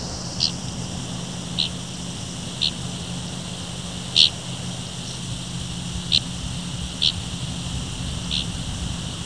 5. Dickcissel
dicksissel.aiff